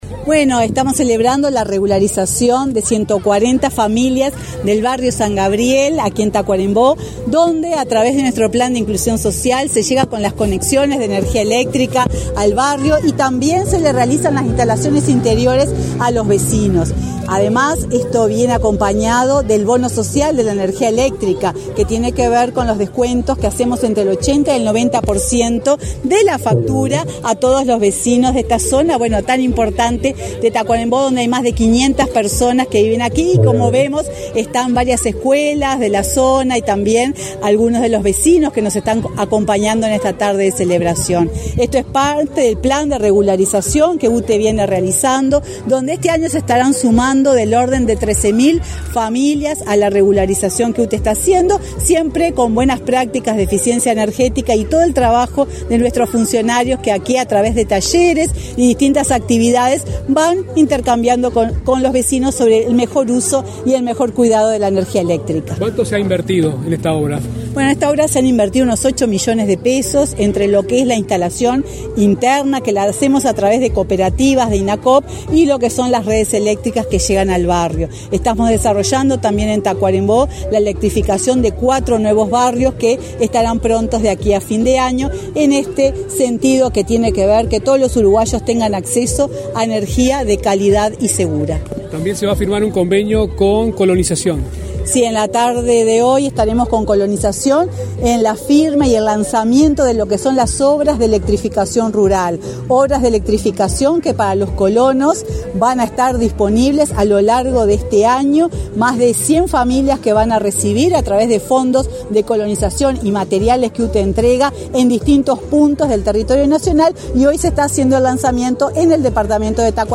Declaraciones a la prensa de la presidenta de UTE, Silvia Emaldi
Declaraciones a la prensa de la presidenta de UTE, Silvia Emaldi 15/08/2023 Compartir Facebook X Copiar enlace WhatsApp LinkedIn Autoridades de UTE participaron en la inauguración de obras de electrificación en el barrio San Gabriel, en Tacuarembó, este 15 de agosto. En la oportunidad, la presidenta de UTE, Silvia Emaldi, realizó declaraciones a la prensa.